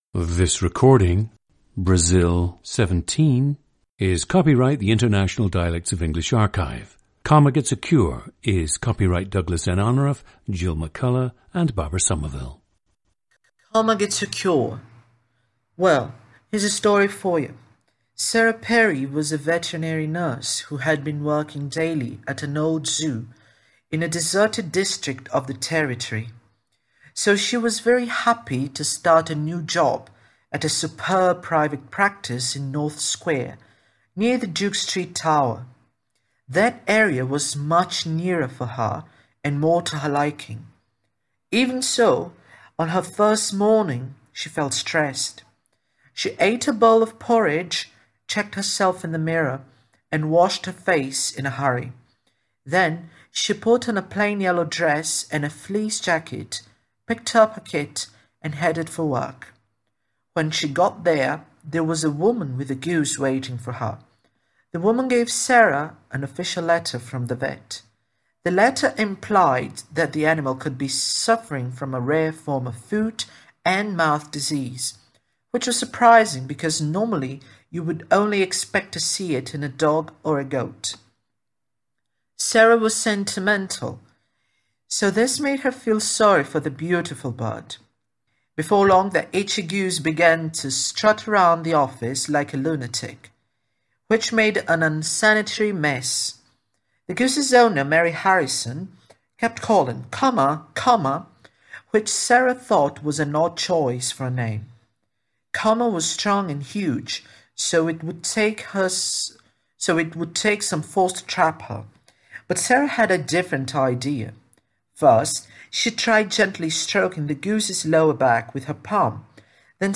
GENDER: male
ETHNICITY: Brazilian/white Latin American
The subject says he has a thick, sometimes stigmatized, accent of Brazilian Portuguese. It mixes both Caipira and Mountain dialects.
• Recordings of accent/dialect speakers from the region you select.
The recordings average four minutes in length and feature both the reading of one of two standard passages, and some unscripted speech.